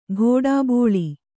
♪ ghōḍābōḷi